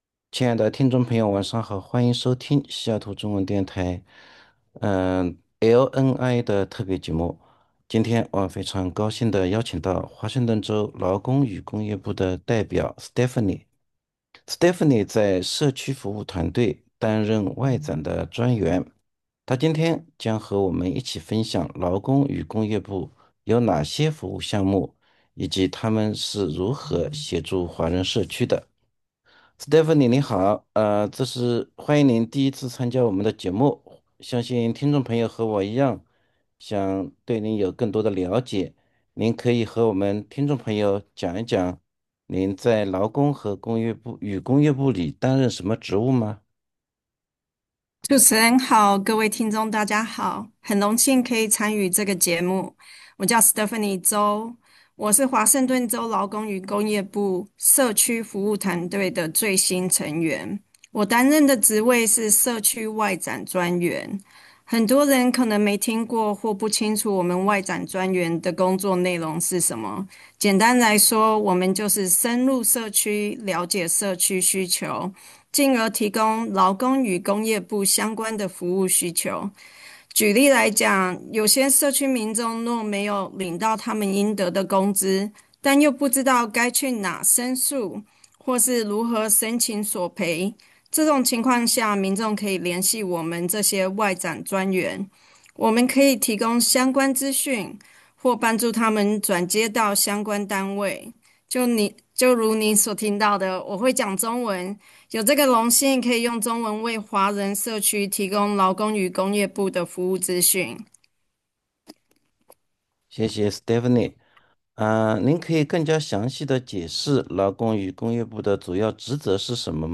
LnI_Interview_0221.mp3